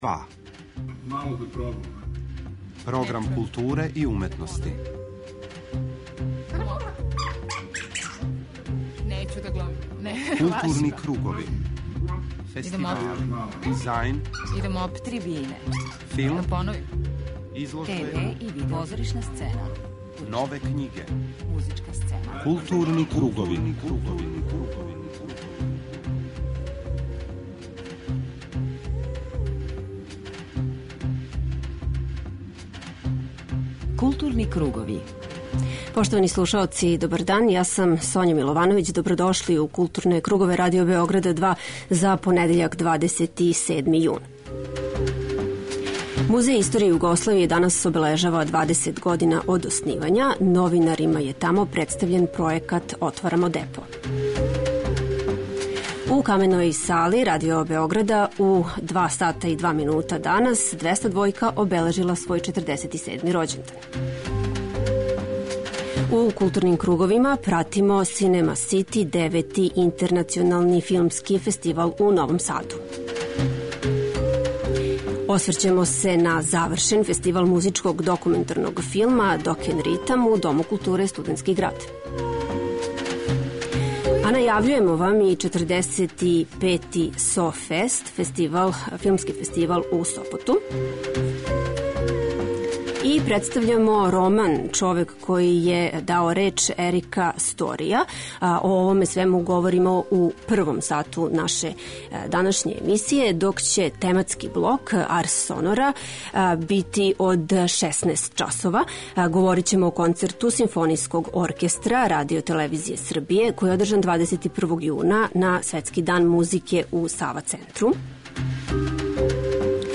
преузми : 41.10 MB Културни кругови Autor: Група аутора Централна културно-уметничка емисија Радио Београда 2.